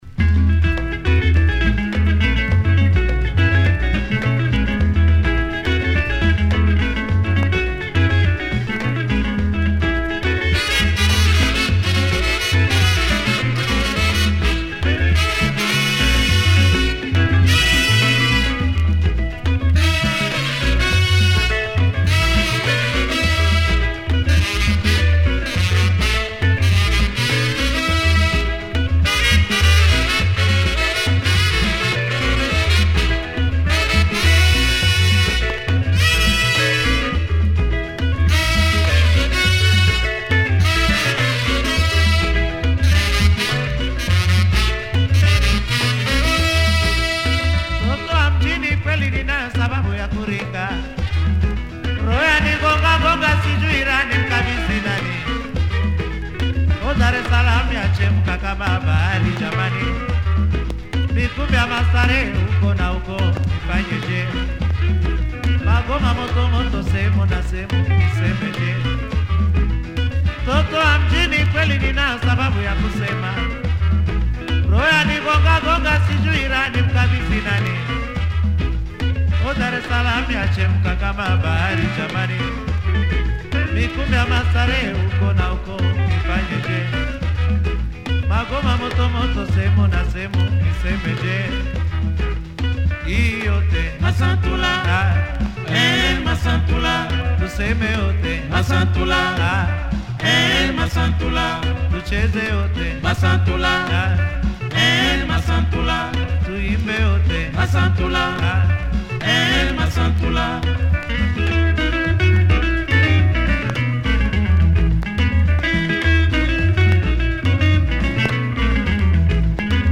orchestra, fab horns.